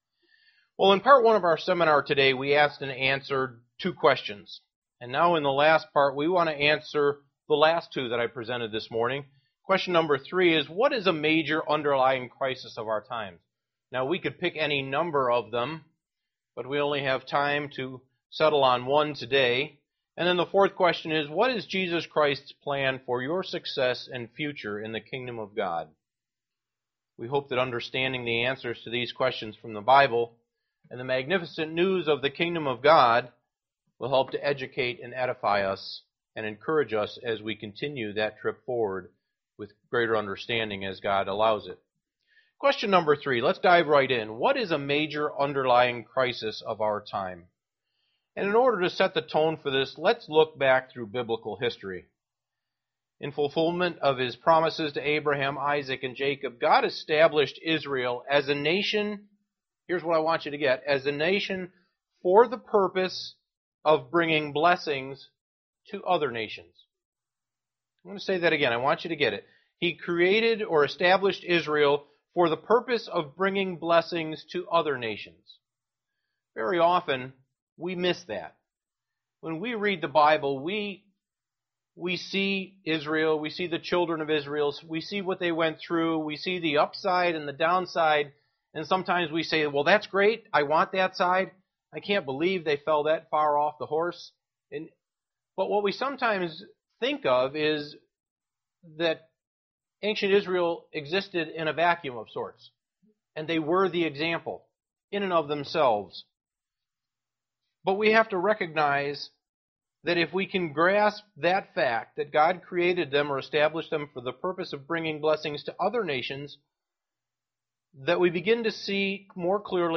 Print KOG Seminar 3) What is the major underlying crisis of our time? 4) What is Christ's plan for your success and future in the Kingdom of God? UCG Sermon Studying the bible?